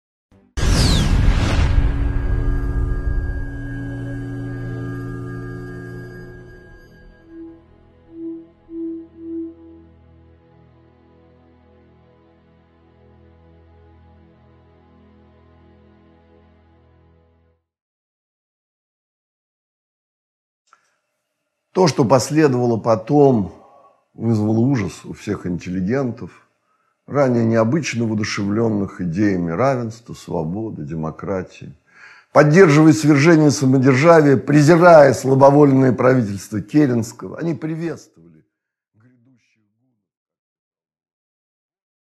Аудиокнига Санкт-Петербург времен революции 1917 года. Эпизод 3 | Библиотека аудиокниг